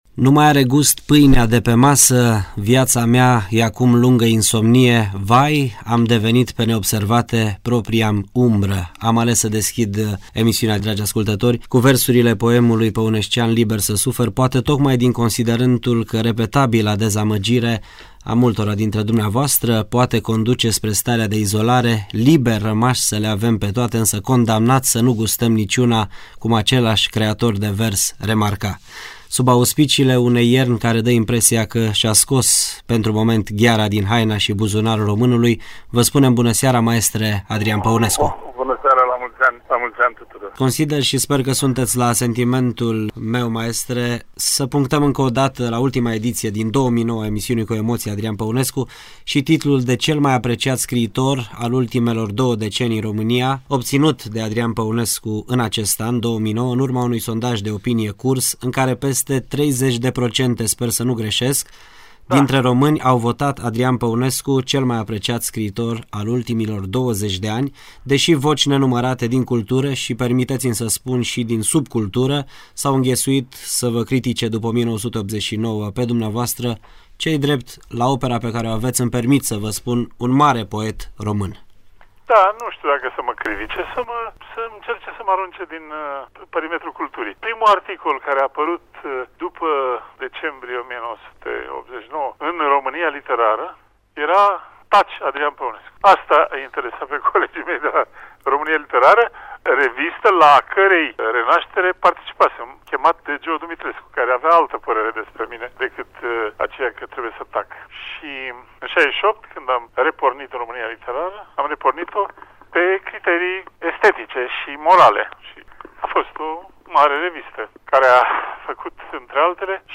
Timp de  cinci ani, Adrian Păuneascu a avut  o emisiune săptămânală la Radio România Reșița, care se difuza în  fiecare zi de  luni, cu o durată de 50 de minute.